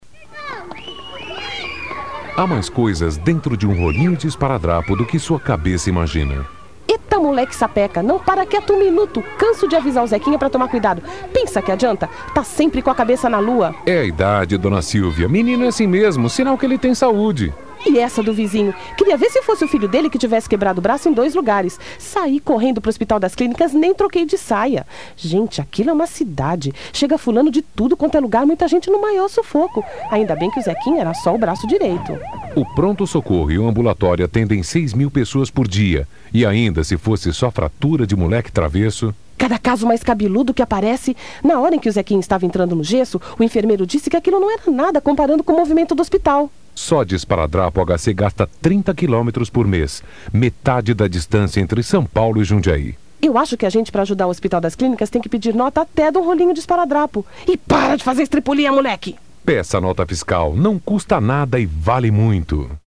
a OBORÉ preparou um pacote de 5 vinhetas de rádio para colaborar em uma campanha contra a sonegação fiscal no estado.